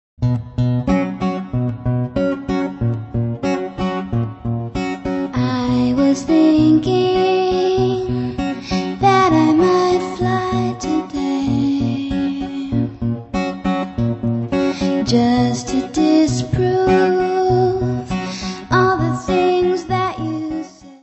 guitarra e voz
sintetizador
baixo eléctrico
bateria
Music Category/Genre:  Pop / Rock